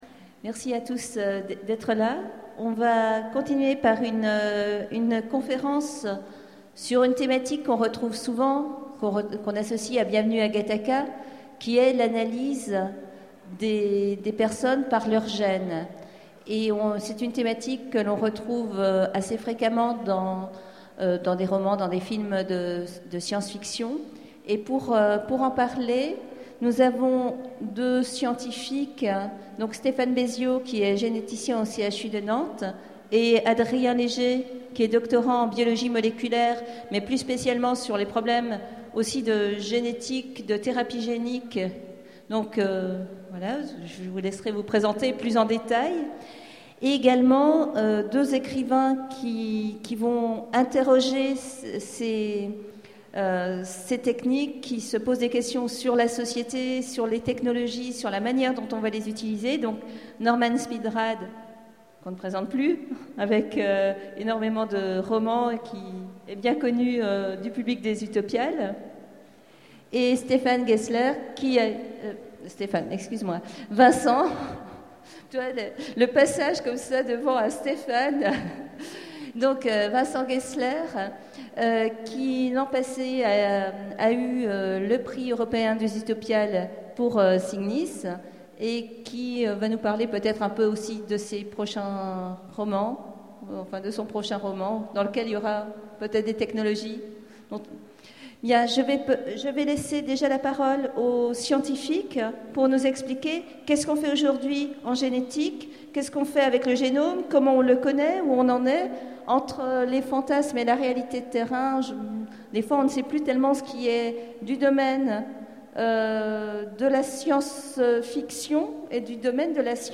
Voici l'enregistrement de la conférence sur la nouvelle "Génomancie".